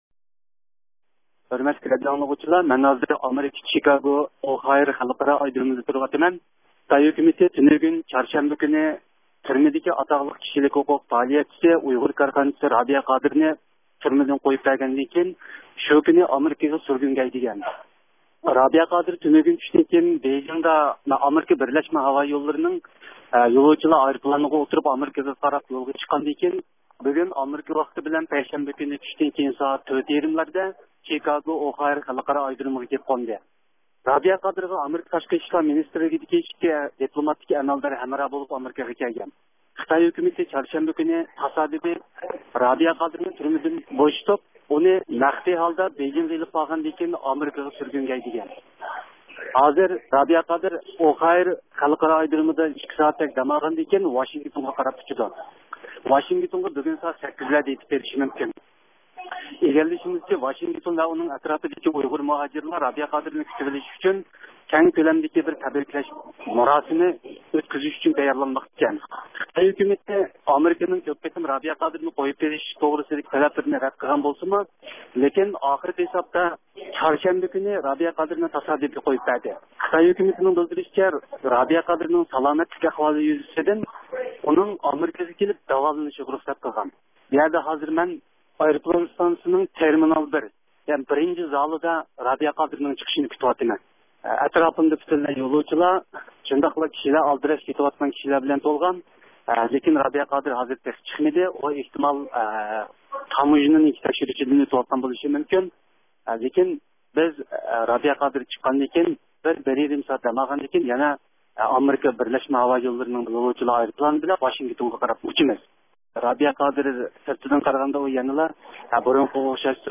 رابىيە قادىر خانىمنى زىيارەت – ئۇيغۇر مىللى ھەركىتى